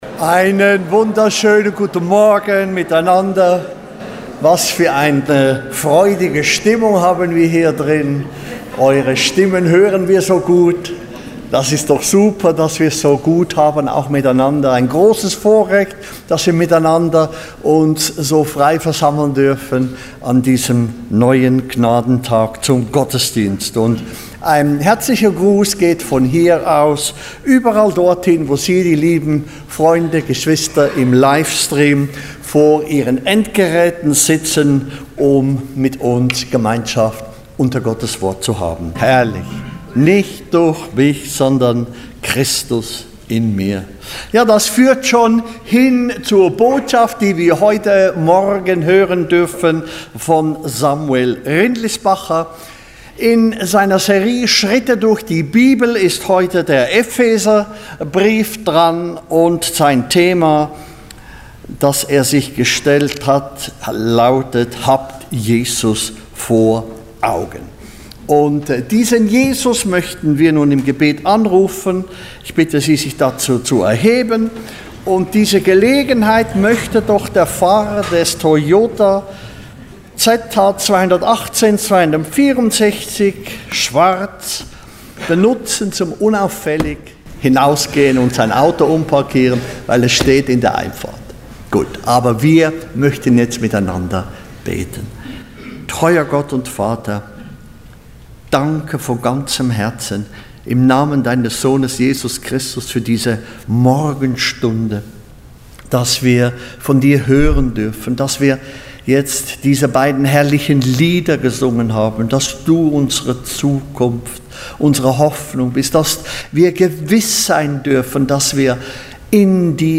Einleitungen Gottesdienst